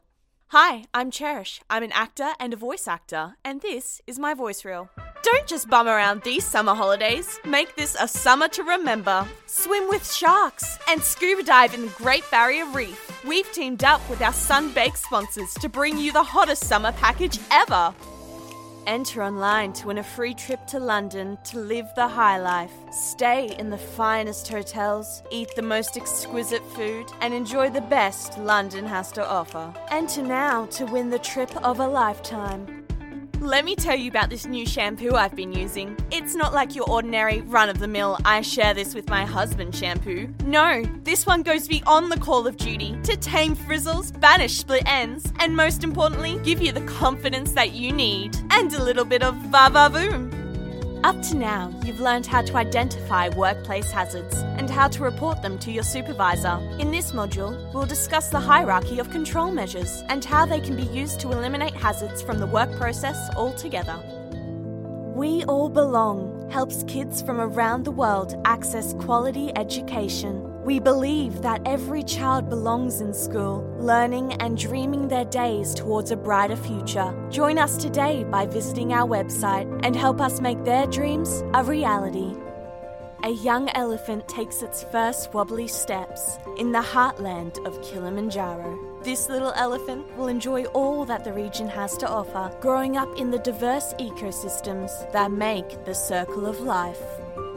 Female Voice Over Talent, Artists & Actors
Yng Adult (18-29)